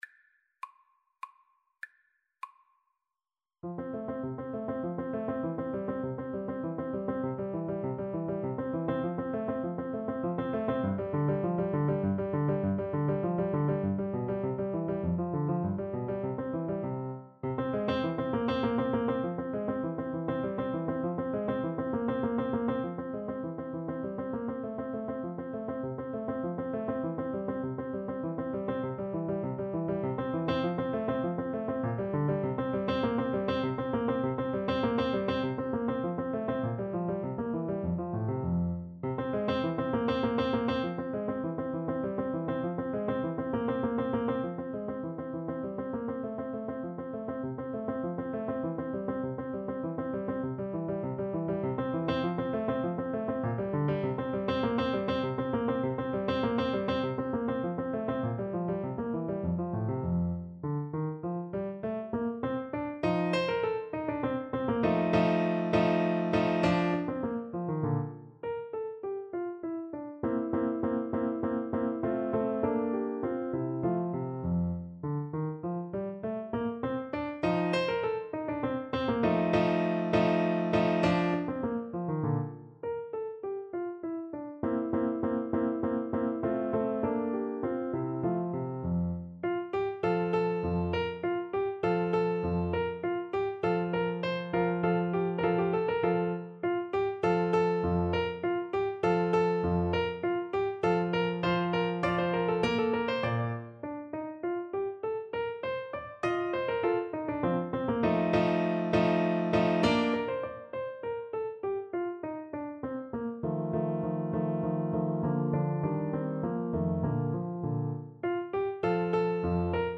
F major (Sounding Pitch) (View more F major Music for Tuba )
3/4 (View more 3/4 Music)
Moderato
Classical (View more Classical Tuba Music)